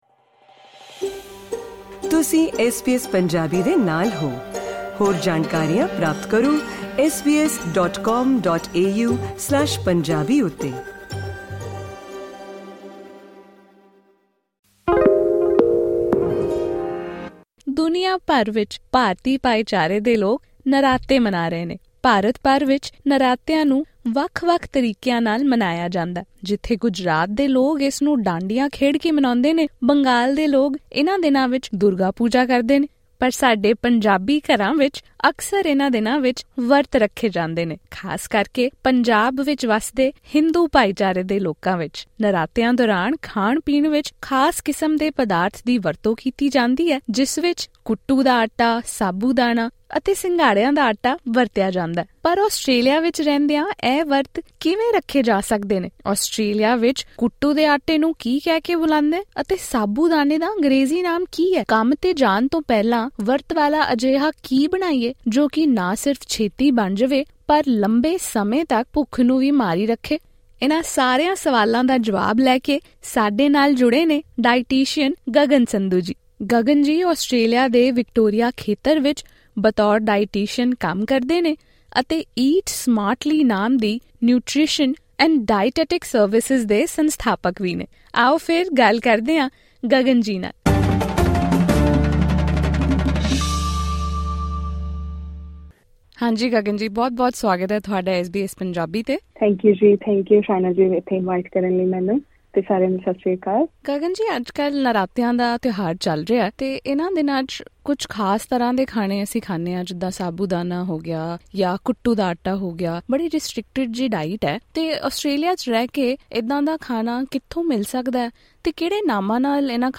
special conversation